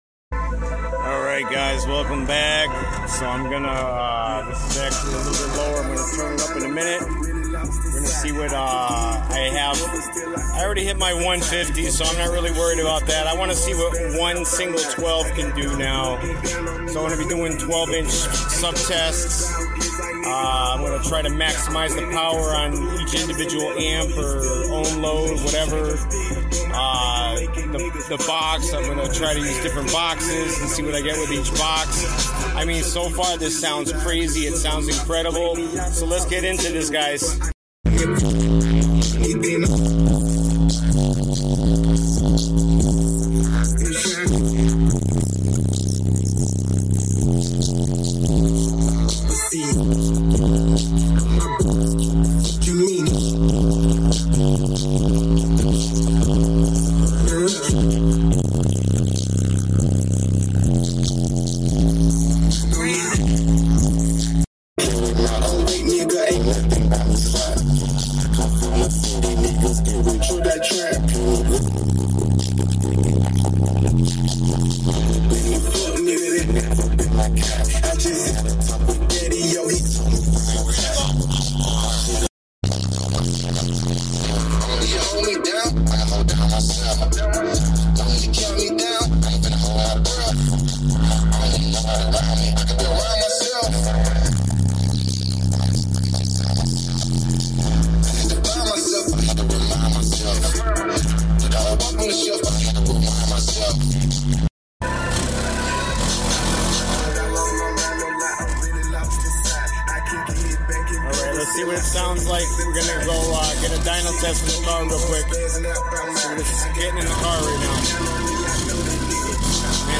American Bass HD VELOCITY 12 Subwoofer sound effects free download
American Bass HD-VELOCITY 12 Subwoofer Mega Flex